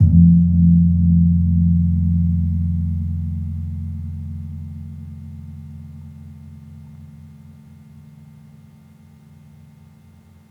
Gong-F1-p.wav